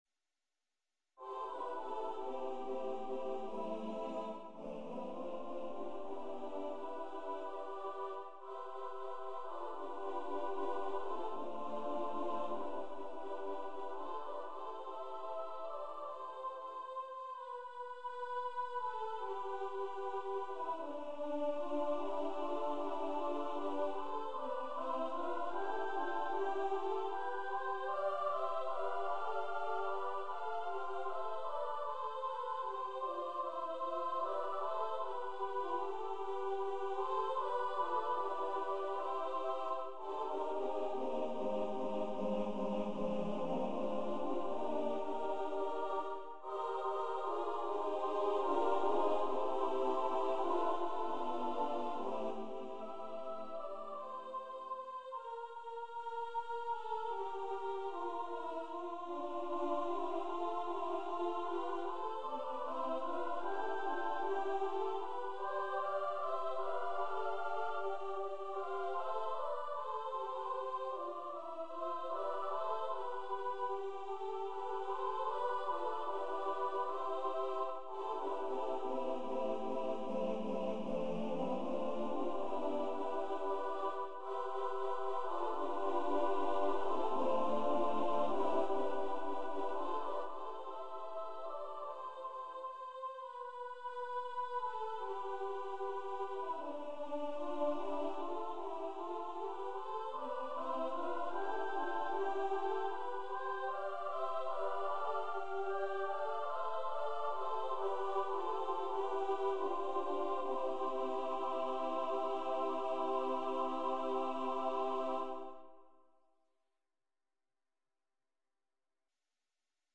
SSSSAAAA Voices, a cappella
Composer's Demo